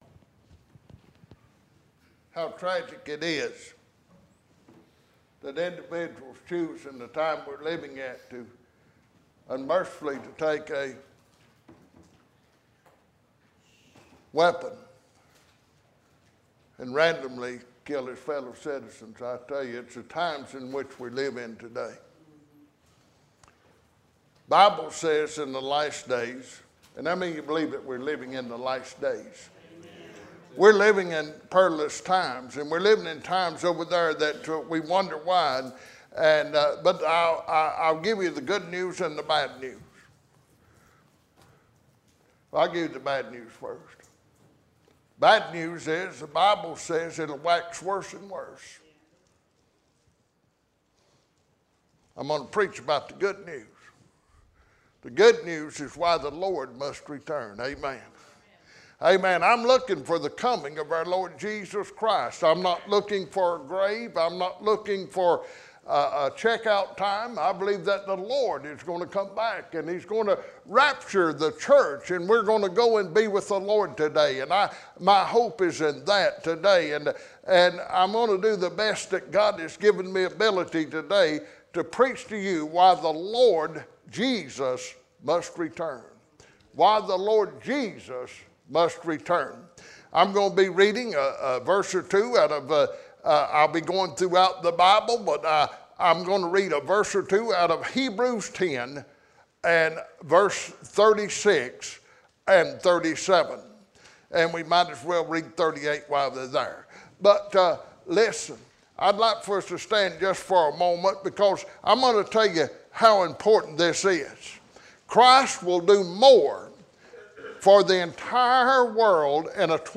2019 Acts Hebrews Jude Philippians Revelation Romans Death Eternal Life hope Judgement Sin Sermon Scripture